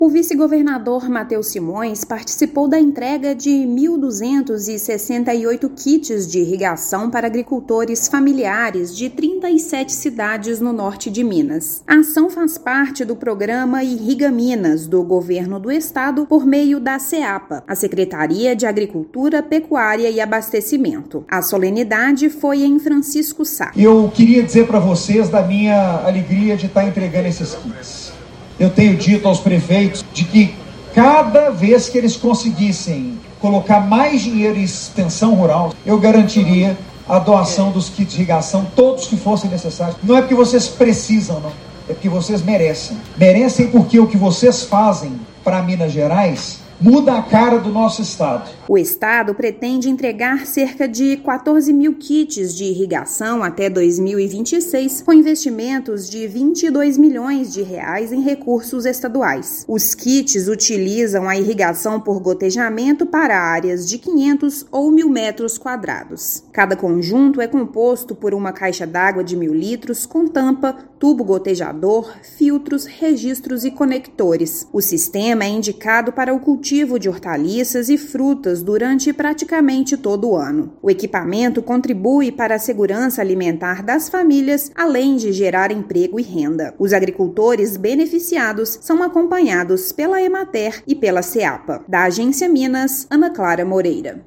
Equipamentos vão garantir o abastecimento das famílias, além da geração de renda com a venda da produção. Ouça matéria de rádio.